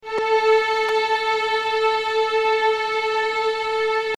Craquement en sortie analogique audio - COMPOSITEUR .ORG
(clique droit et "enregistrer sous") J'y jette une oreille dès que j'ai un peu de temps (ça ne ressemble pas à une satu déjà, et ce n'est pas non plus un vrai "craquement").
craquement.mp3